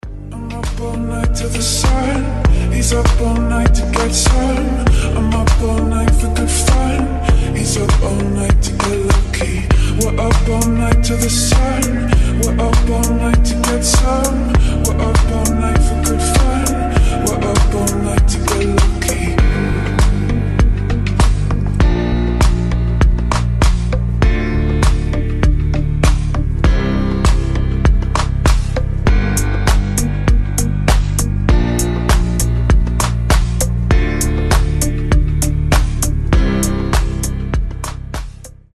• Качество: 320, Stereo
мужской голос
deep house
релакс
чувственные
Chill
ремиксы
Кайфовая романтичная нарезка на звонок